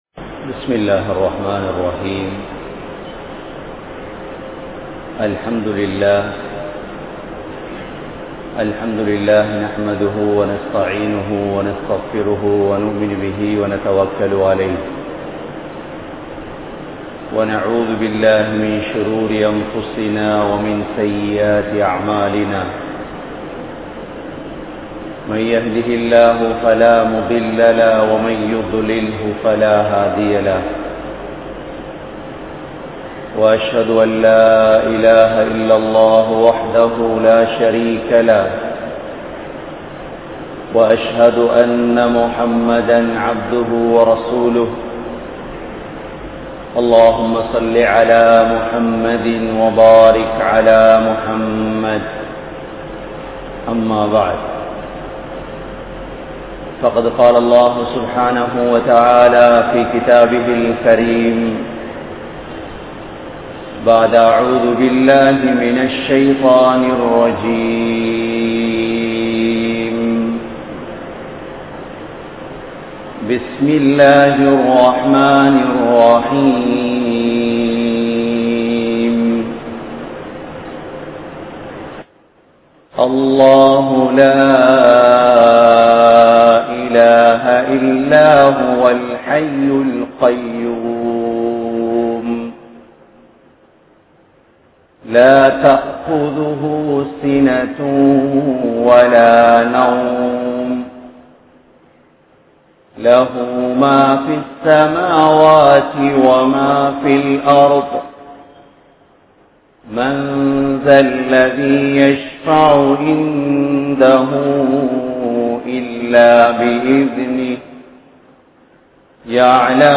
Atputhamaana Aayathul Qursi (அற்புதமான ஆயத்துல் குர்ஸி) | Audio Bayans | All Ceylon Muslim Youth Community | Addalaichenai
Grand Jumua Masjith